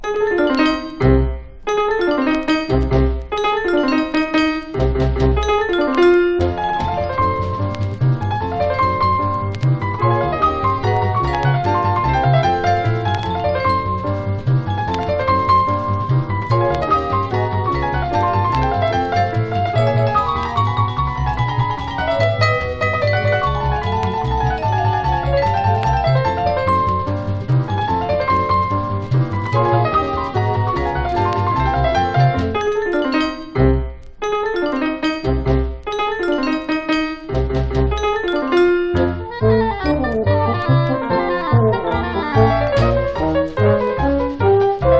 Jazz　USA　12inch　33rpm　Mono